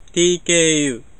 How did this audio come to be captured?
Tech. description: (44.1k, 16bit, mono)